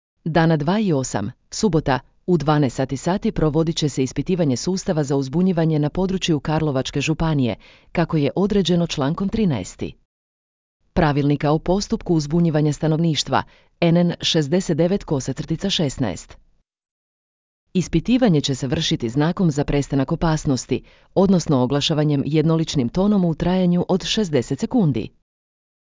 Ispitivanje će se vršiti znakom za prestanak opasnosti, odnosno oglašavanjem jednoličnim tonom u trajanju od 60 sekundi.